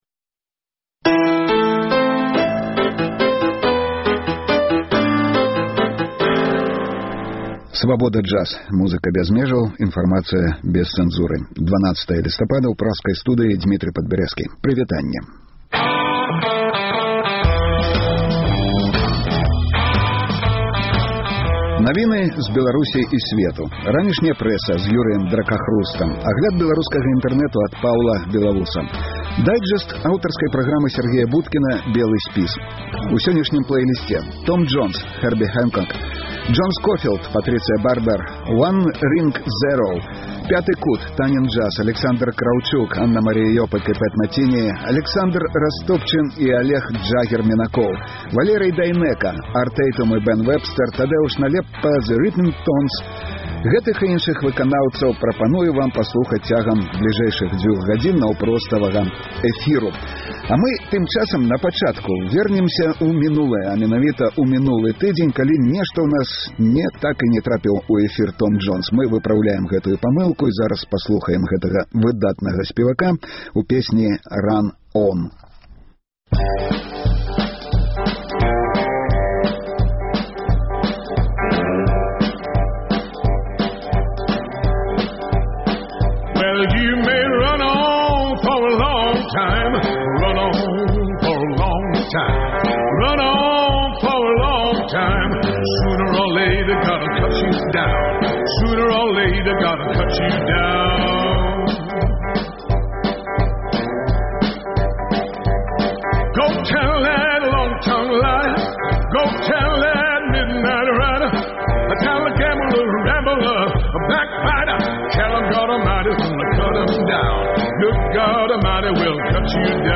Слухайце ад 12:00 да 14:00 жывы эфір "Свабоды"!